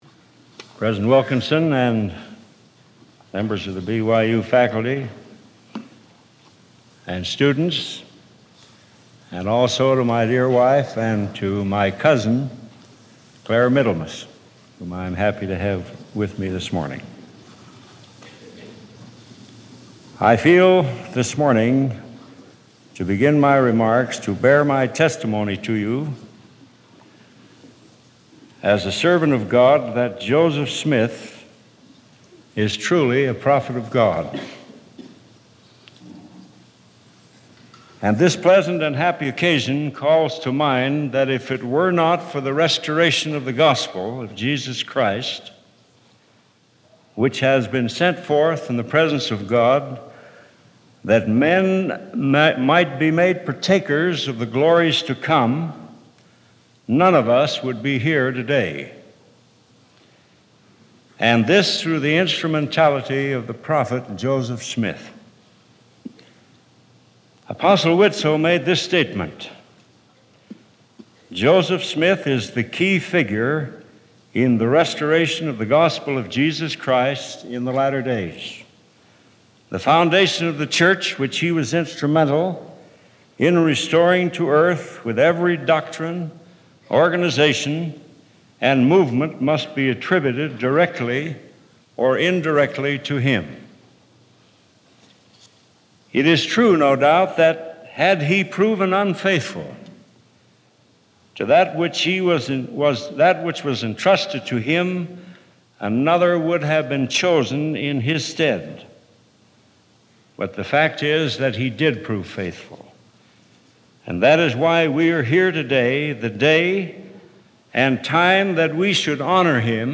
Devotional
Alvin R. Dyer was an assistant to the Quorum of the Twelve Apostles in The Church of Jesus Christ of Latter-day Saints when he delivered this devotional address at Brigham Young University on February 7, 1967.